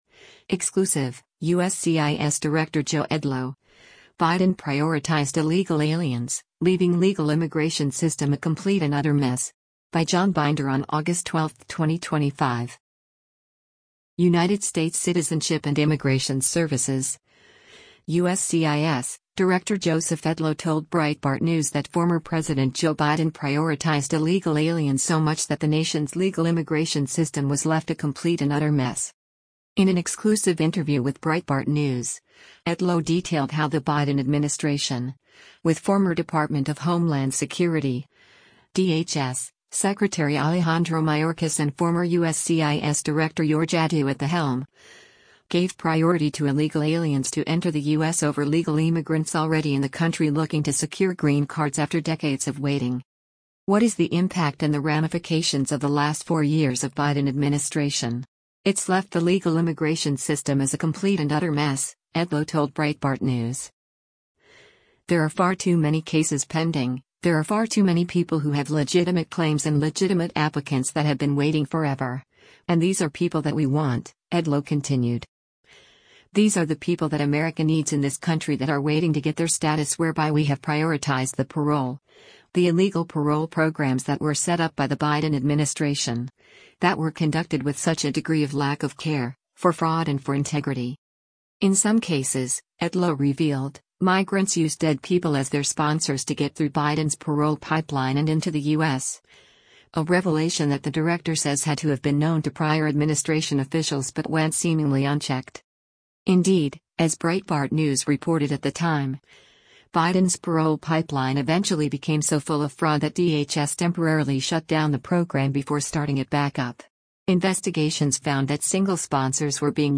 In an exclusive interview with Breitbart News, Edlow detailed how the Biden administration, with former Department of Homeland Security (DHS) Secretary Alejandro Mayorkas and former USCIS Director Ur Jaddou at the helm, gave priority to illegal aliens to enter the U.S. over legal immigrants already in the country looking to secure green cards after decades of waiting.